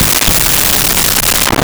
Wrong Answer
WRONG ANSWER.wav